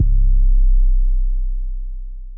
TM808.wav